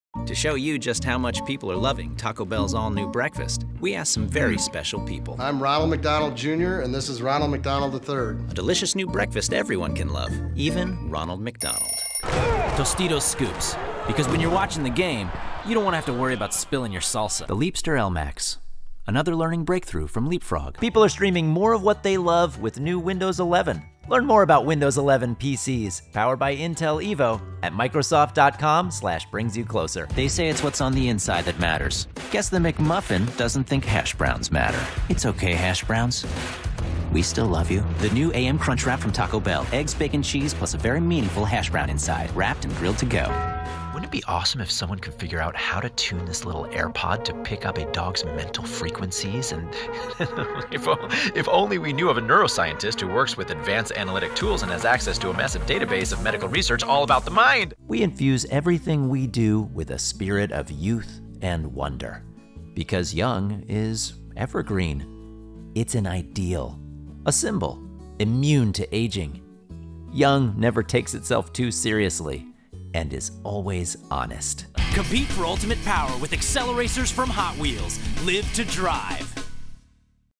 COMMERCIAL VO Demo (click here for mp3)